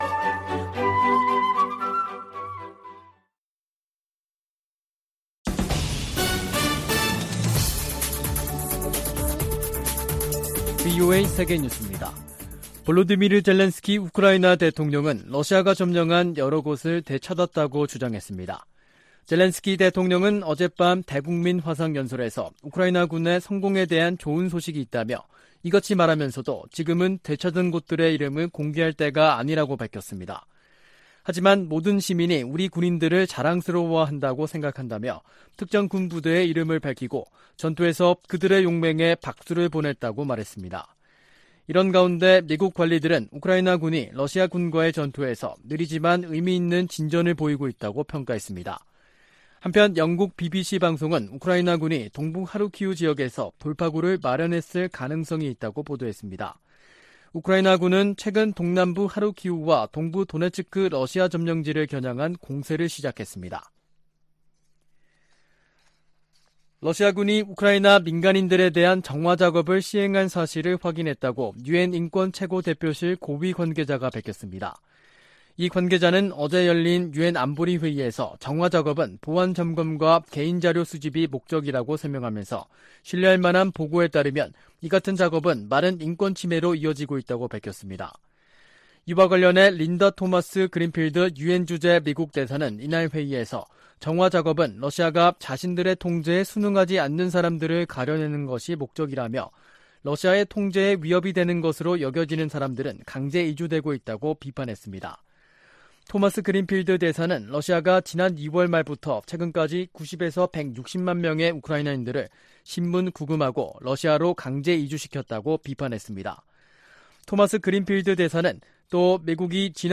VOA 한국어 간판 뉴스 프로그램 '뉴스 투데이', 2022년 9월 8일 2부 방송입니다. 카멀라 해리스 미국 부통령이 오는 25일부터 29일까지 일본과 한국을 방문할 계획이라고 백악관이 밝혔습니다. 한국 외교부와 국방부는 제3차 미한 외교·국방 2+2 확장억제전략협의체(EDSCG) 회의가 오는 16일 워싱턴에서 열린다고 밝혔습니다. 한국 정부가 북한에 이산가족 문제 해결을 위한 당국간 회담을 공식 제안했습니다.